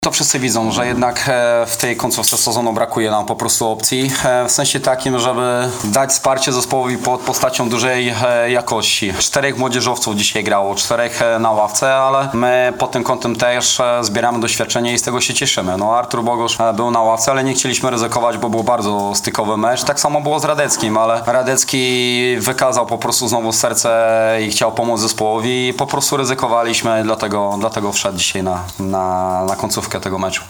Na pomeczowej konferencji prasowej szkoleniowiec biało-niebieskich